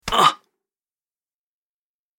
دانلود آهنگ تصادف 25 از افکت صوتی حمل و نقل
جلوه های صوتی
دانلود صدای تصادف 25 از ساعد نیوز با لینک مستقیم و کیفیت بالا